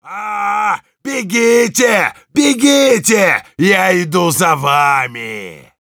Sounds Yell Rus
Heavy_yell15_ru.wav